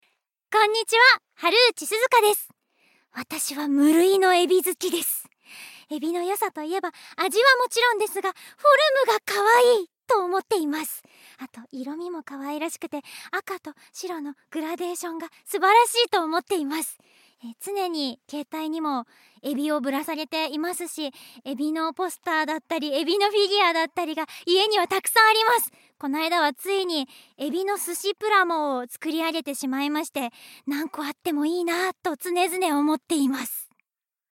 ボイスサンプル
フリートーク